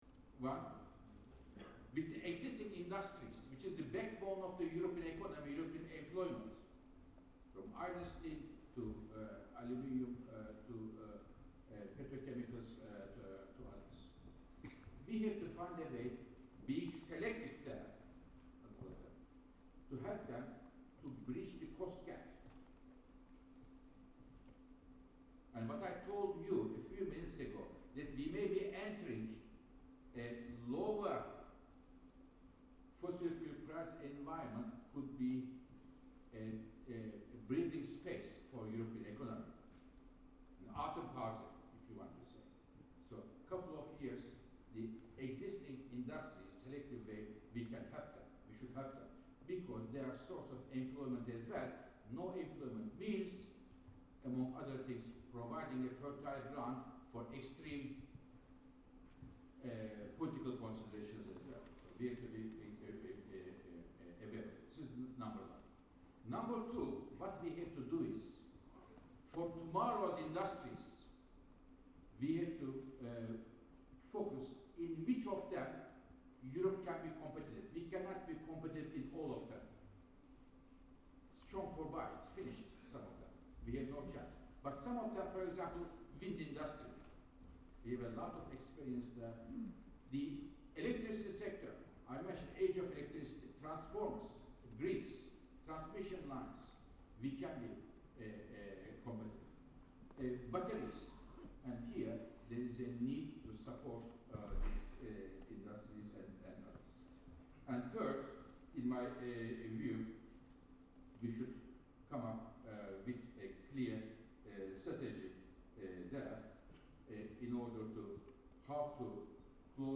Das Publikum musste lange rund 50 Minuten warten, doch dann, auf Nachfrage, kamen entscheidende Worte:
Über den Umgang mit der Industrie emphielt Birol: